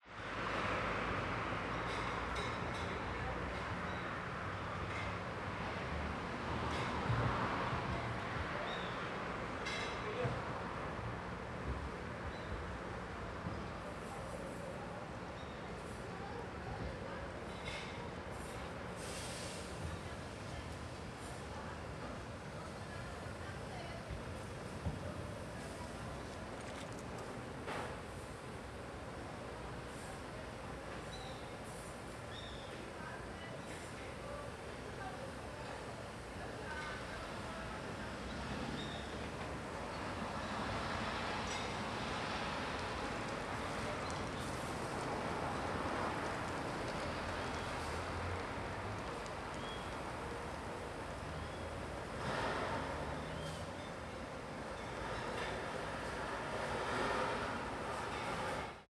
CSC-08-021-GV - Ambiencia em Area Externa do Restaurante Universitario da Universidade de Brasilia.wav